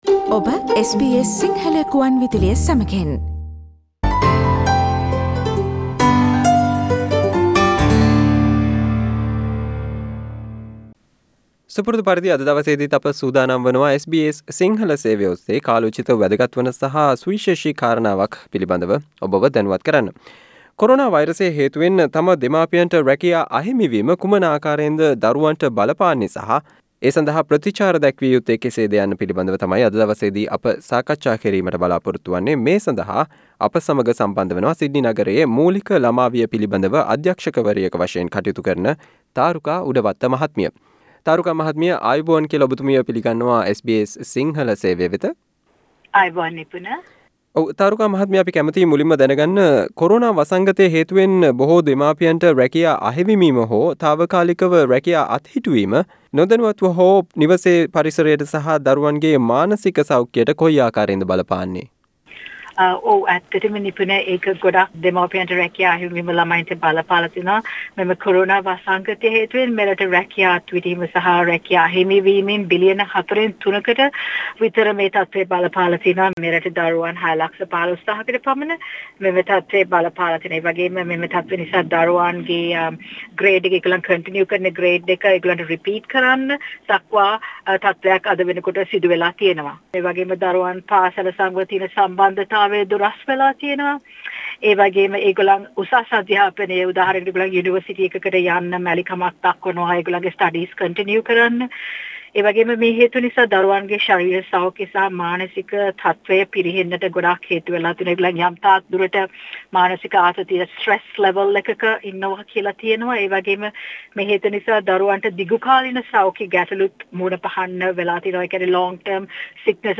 SBS interview